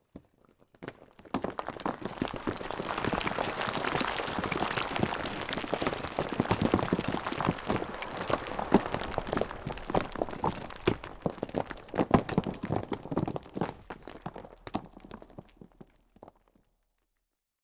fx_crawlerexplosion_debris.wav